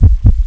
assets/common/sounds/player/heart.wav at main
heart.wav